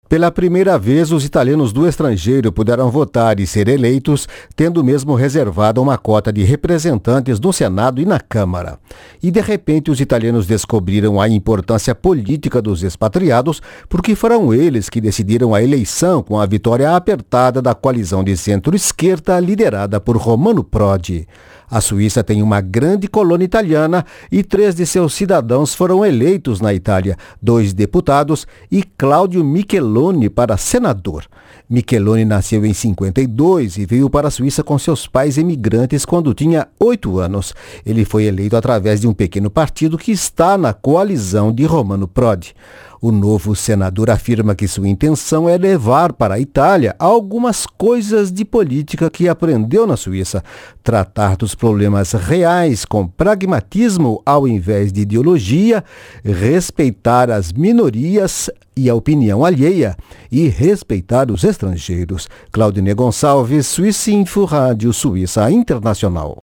Em entrevista a swissinfo, Micheloni diz que pretende levar um de cultura suíça à política italiana.